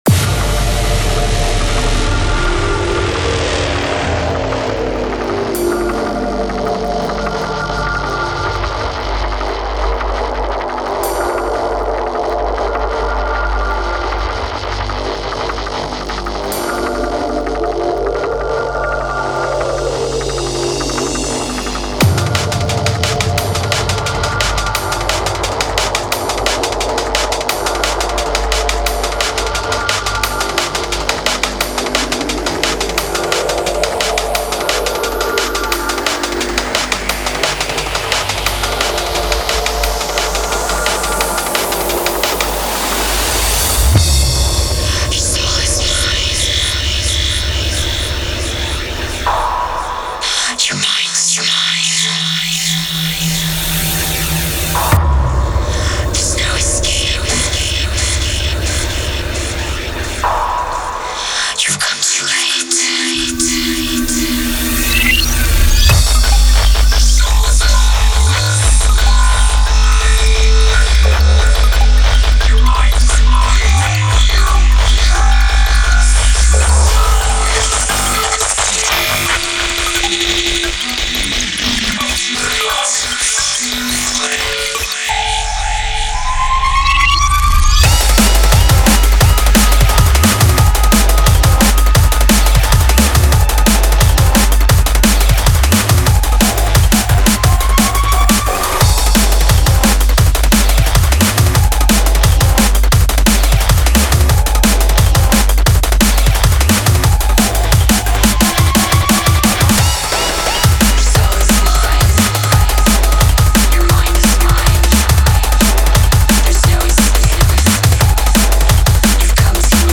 Style: Drum and Bass, Dubstep